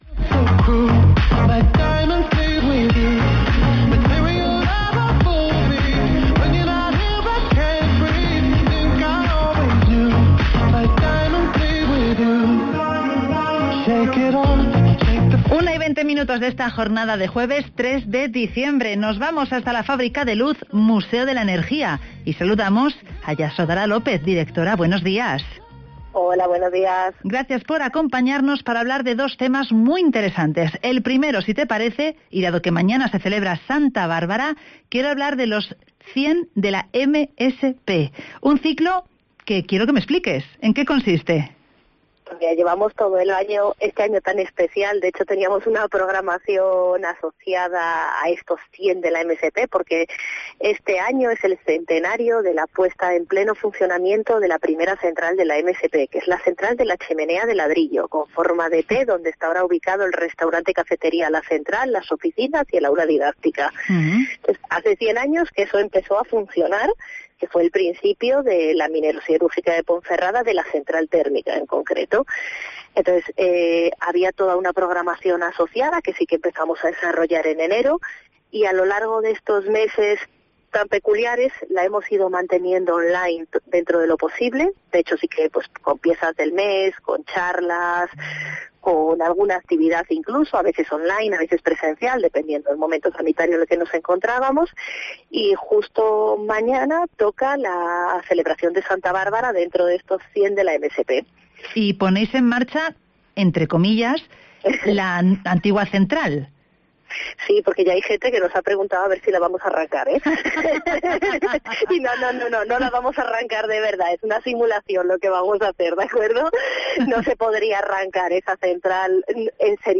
Nos acercamos al Museo de la Energía de Ponferrada (Entrevista